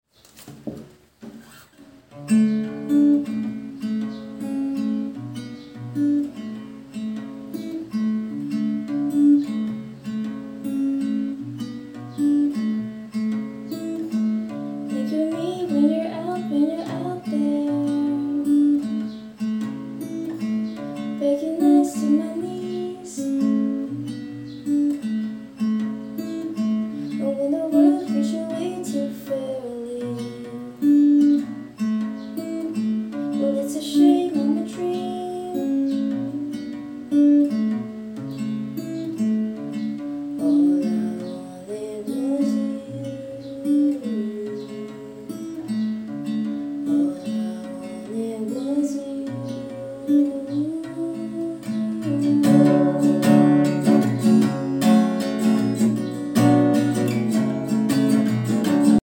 some bathroom acoustics for this one
the most horrible singing and guitar playing